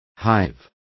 Complete with pronunciation of the translation of hive.